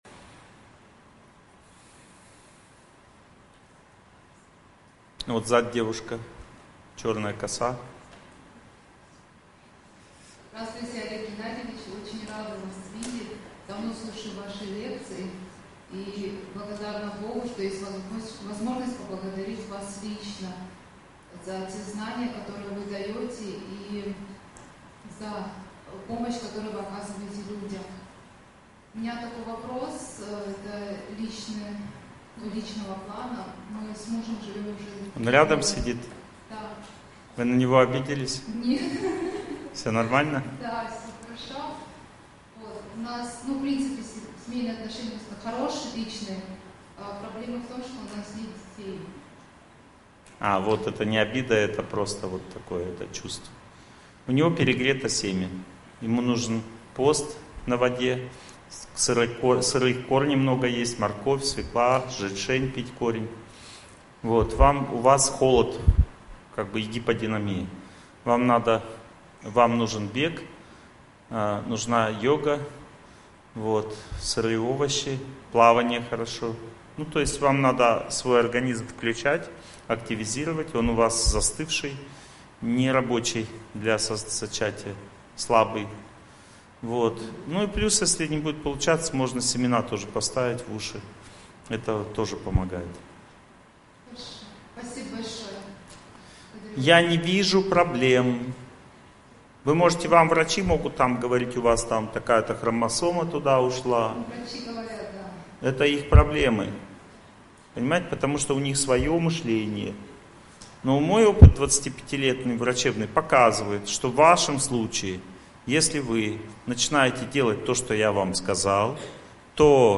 Raskritie-svoego-prednaznacheniya-Lekciya-2.mp3